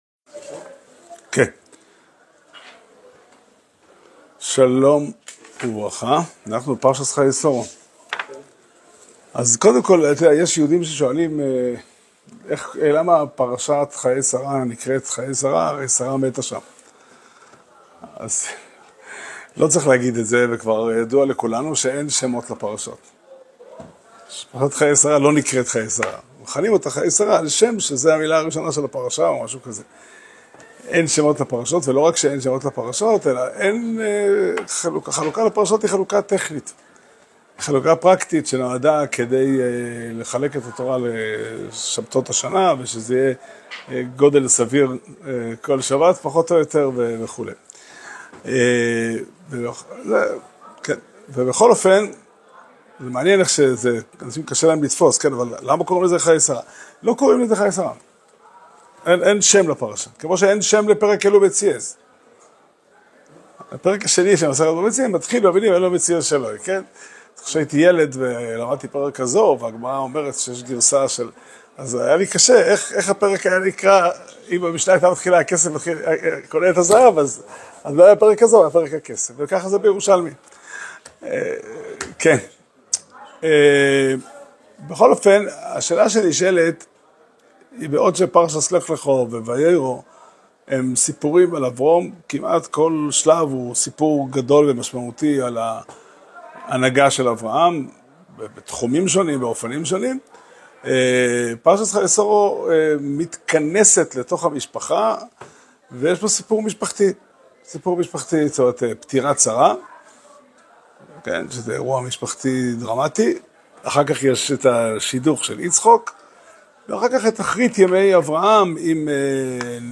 שיעור שנמסר בבית המדרש פתחי עולם בתאריך י"ז חשוון תשפ"ה